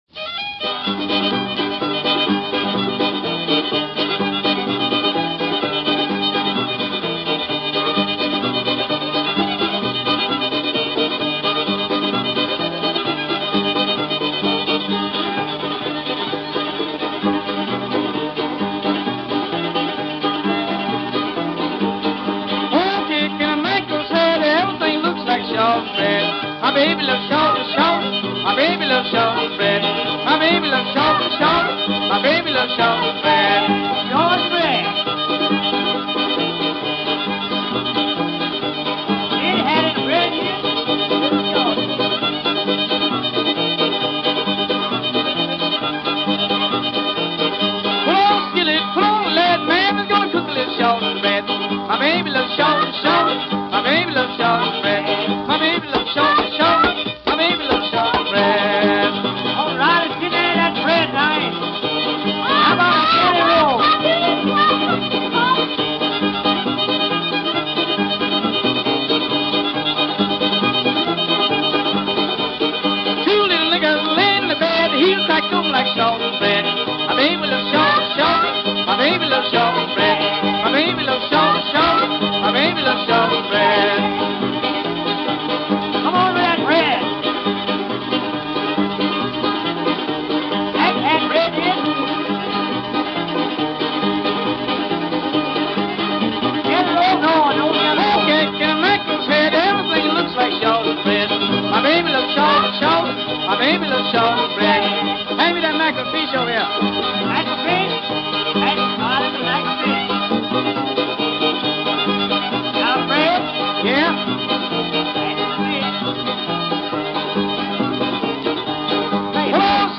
The traditional tune